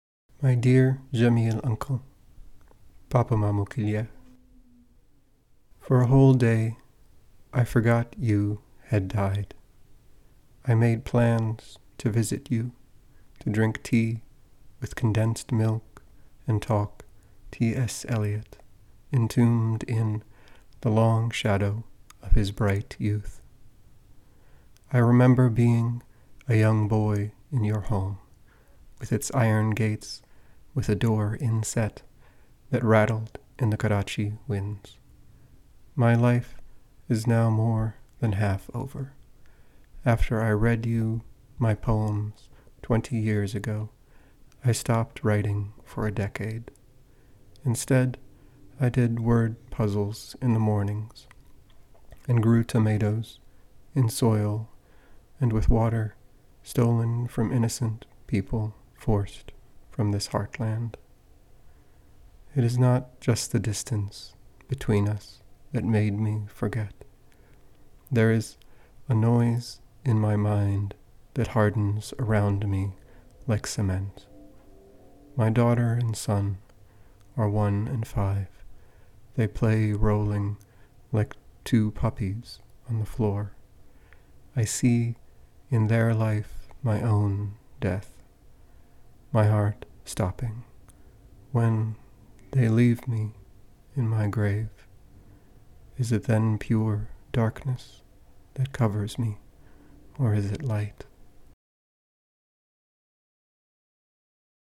POETRY